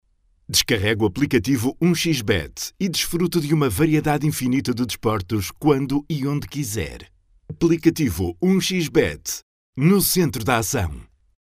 locutor de Portugal